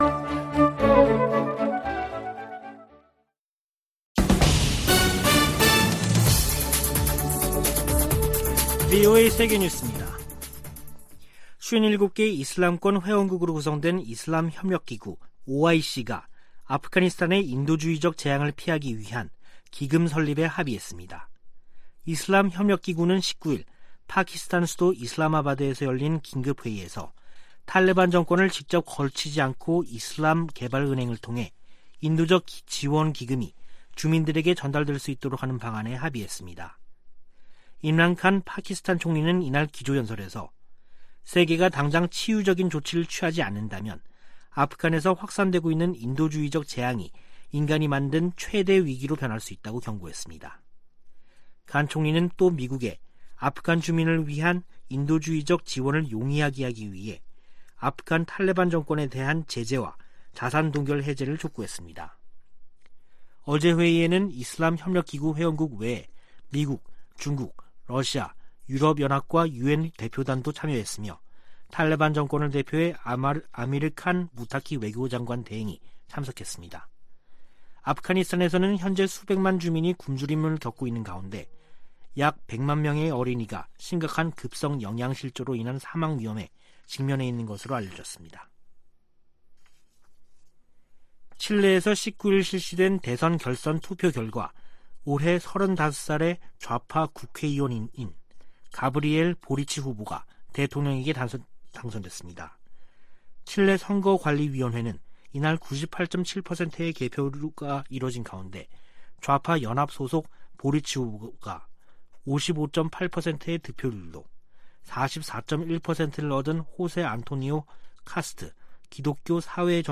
VOA 한국어 간판 뉴스 프로그램 '뉴스 투데이', 2021년 12월 20일 3부 방송입니다. 조 바이든 미국 행정부의 대북정책은 전임 두 행정부 정책의 중간이라는 점을 제이크 설리번 국가안보보좌관이 거듭 확인했습니다. 북한이 내년 잠수함발사탄도미사일(SLBM)을 실전 배치할 것으로 예상된다는 보고서가 나왔습니다. 내년 1월 열리는 핵무기확산금지조약 평가회의에서 북 핵 문제가 논의될 것이라고 미 국무부 선임 고문이 밝혔습니다.